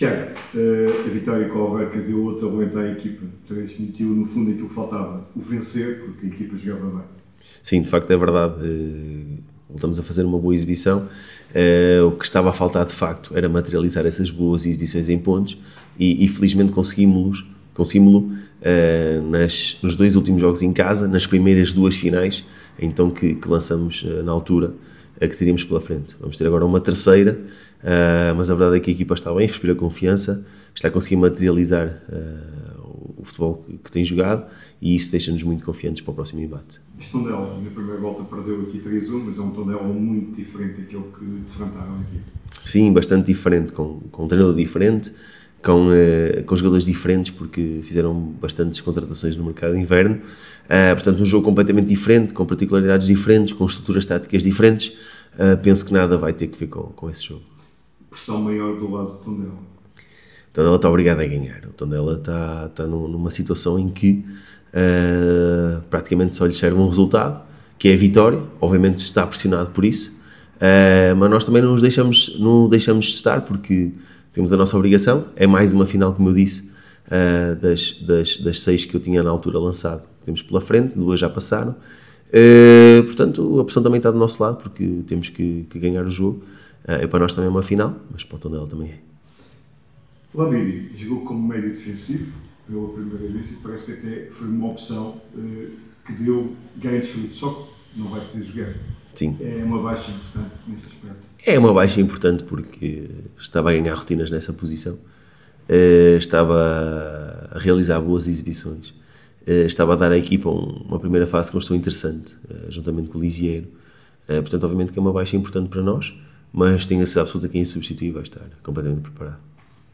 Na conferência de imprensa de antevisão ao encontro com o conjunto beirão